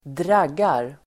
dragga verb, dragGrammatikkommentar: A & (efter B/x)Uttal: [²dr'ag:ar] Böjningar: draggade, draggat, dragga, draggarDefinition: söka på sjöbotten med hjälp av dragg